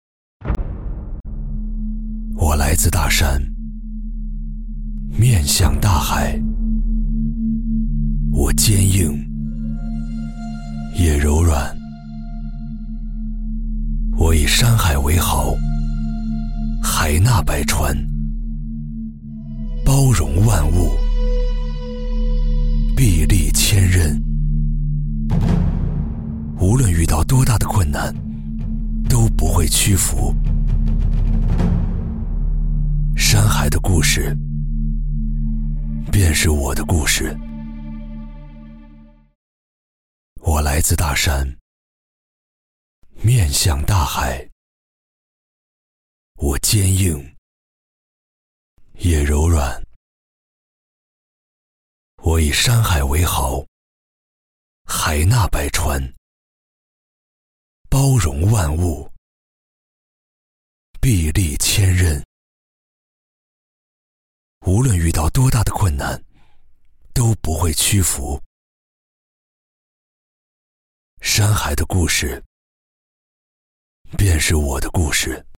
Anuncios
Clean audio with no breaths or mouth noises
Sennheiser MKH 416 Mic, UA Volt 276 Interface, Pro Recording Booth, Reaper
BarítonoBajo